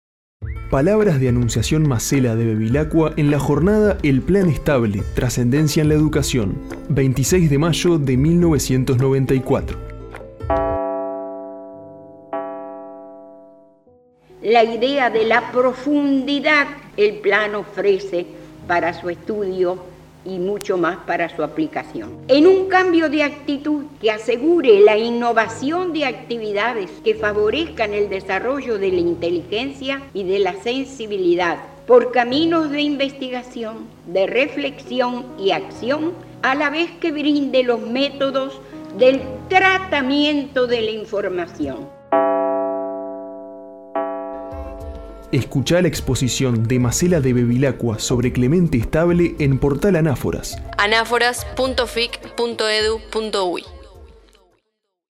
Esta serie recupera fragmentos de obras literarias, entrevistas, citas y conferencias; a través de textos y narraciones con las voces de poetas, periodistas y académicos que integran el repositorio.